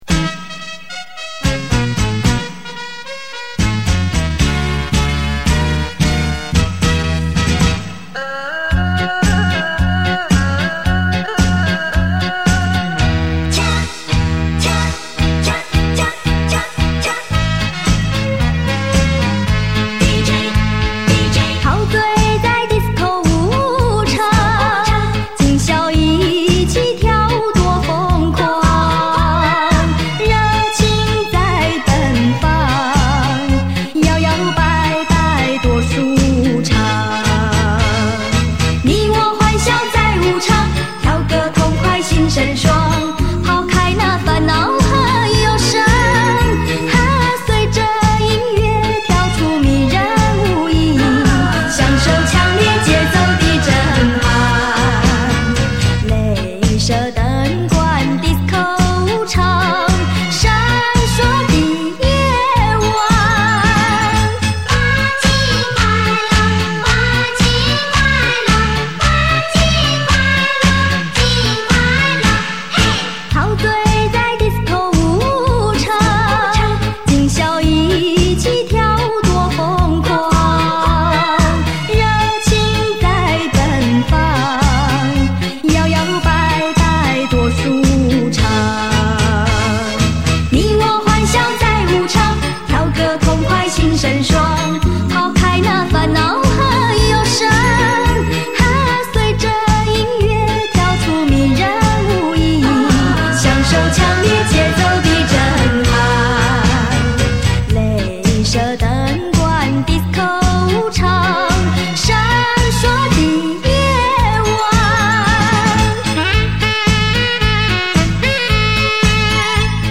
很动听